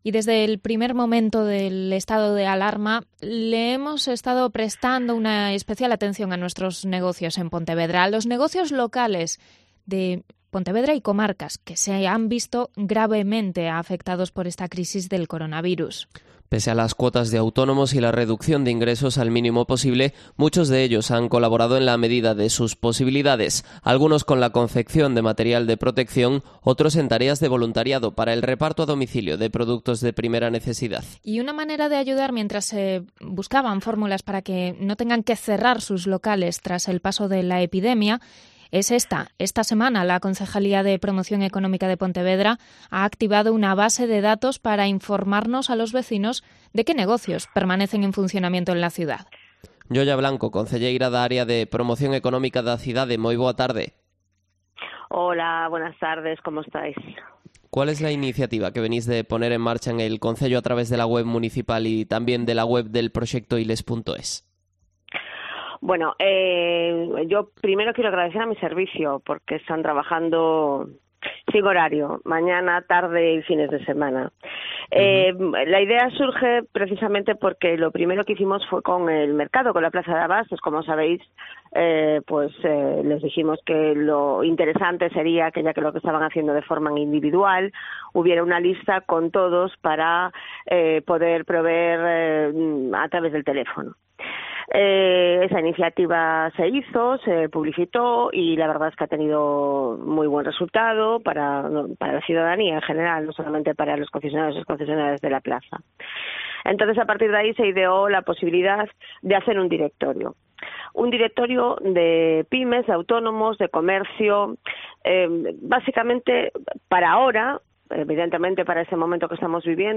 Entrevista a Yoya Blanco, concejala de Promoción Económica de Pontevedra